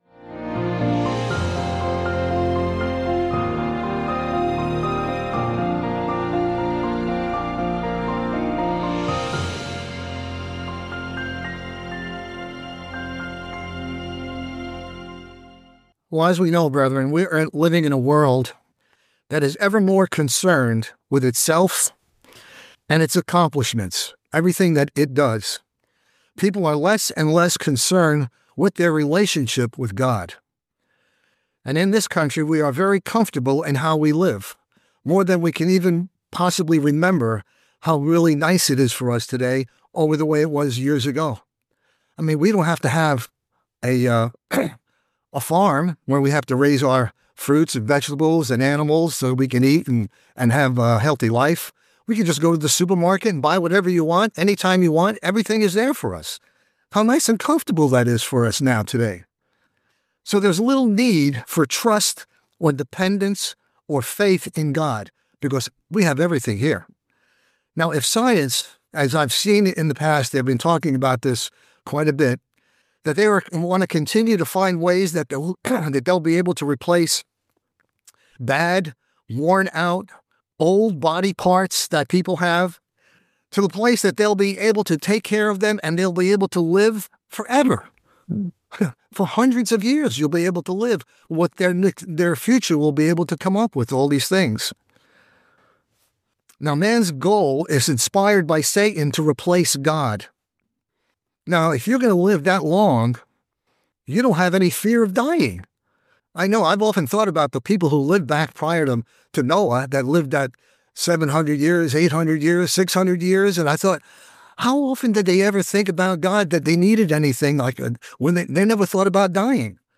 Sermon
Given in Charlotte, NC Hickory, NC Columbia, SC